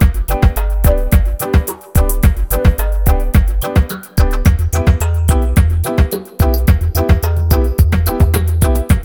RAGGALOOP4-R.wav